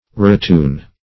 ratoon - definition of ratoon - synonyms, pronunciation, spelling from Free Dictionary
Search Result for " ratoon" : The Collaborative International Dictionary of English v.0.48: Ratoon \Ra*toon"\ (r[.a]*t[=oo]n"), n. 1.